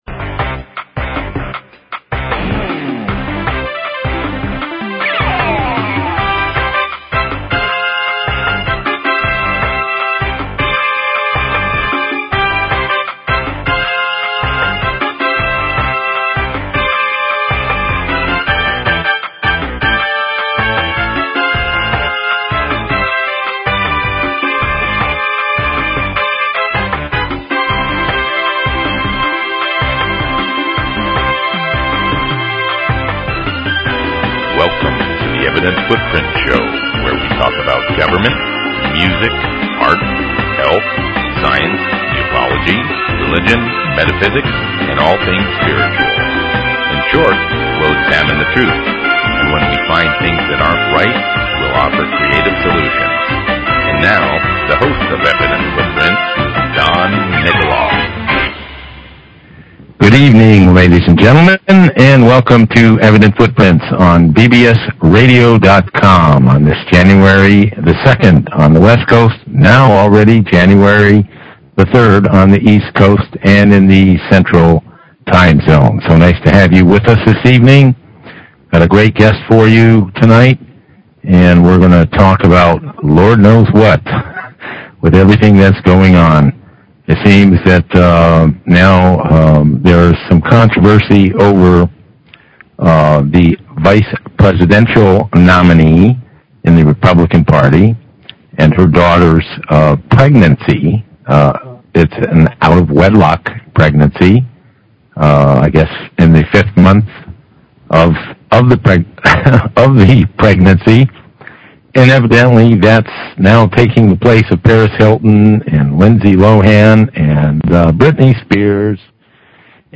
Environmental Scientist, Health Consultant, Herbalist